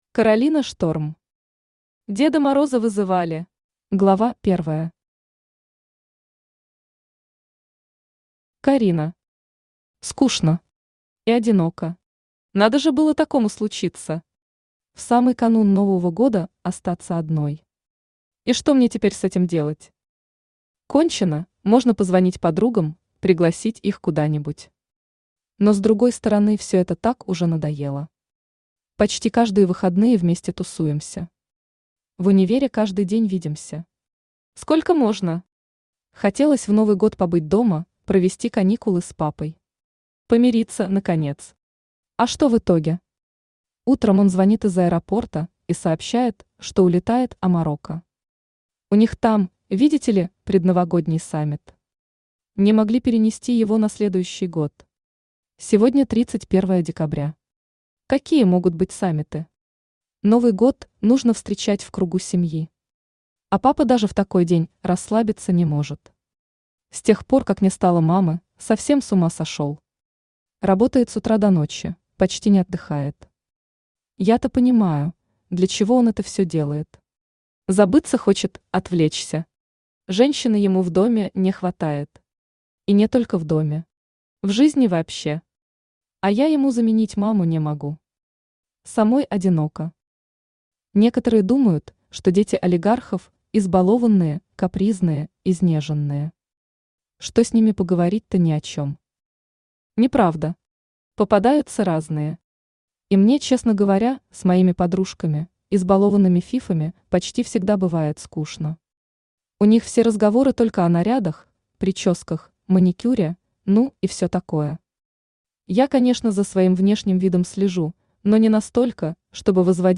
Автор Каролина Шторм Читает аудиокнигу Авточтец ЛитРес.